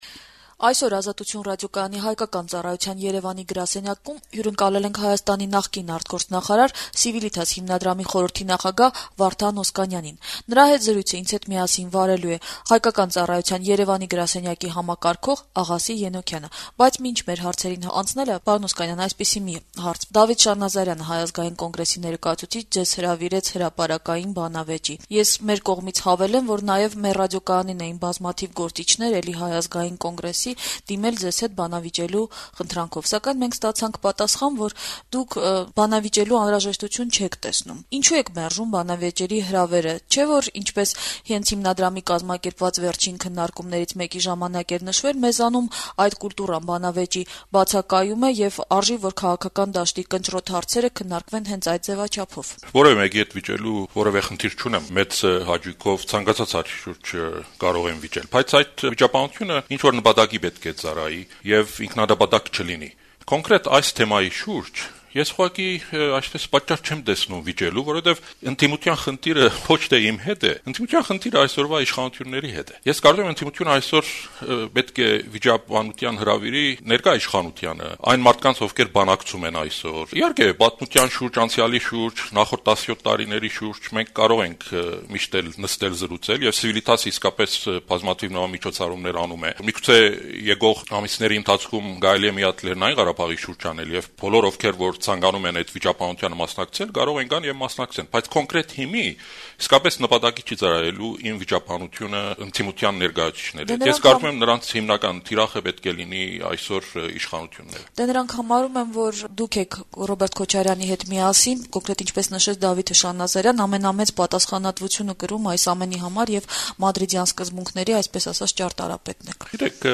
Վարդան Օսկանյանի հարցազրույցը